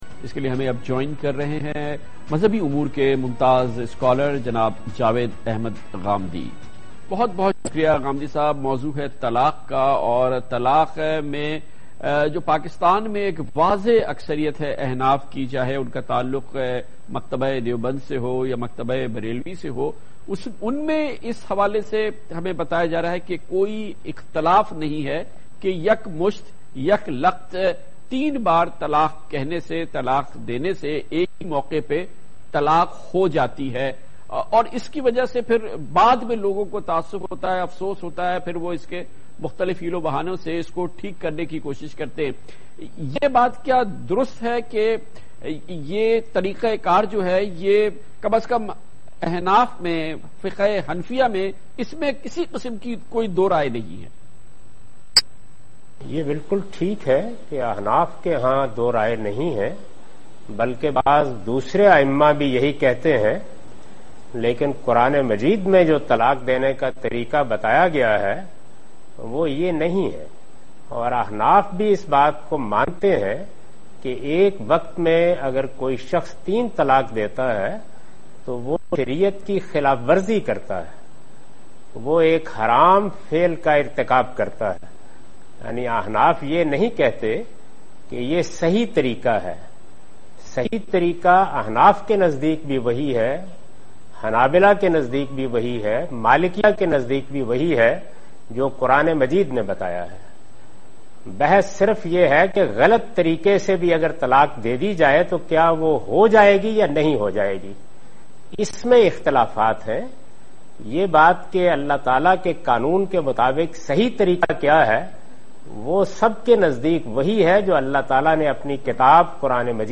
TV Programs
Category: TV Programs / Dunya News / Questions_Answers /
In this program Javed Ahmad Ghamidi speaks about "Triple Divorce Issue in India" in program "Kamran Khan Kay Sath" on Dunya News
دنیا نیوز کے پروگرام "کامران خان کے ساتھ" میں جاوید احمد غامدی "ہندوستان میں تین طلاقوں کا مسئلہ" کے متعلق سوالات کے جواب دے رہے ہیں